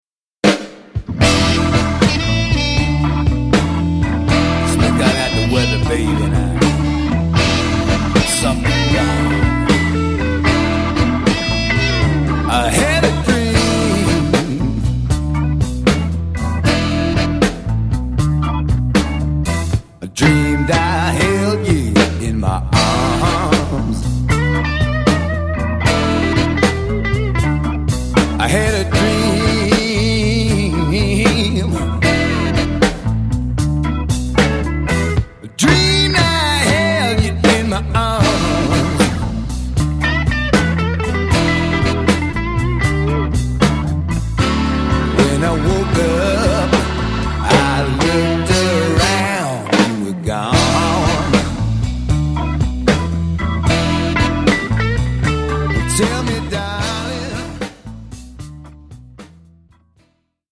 vocal & harmonica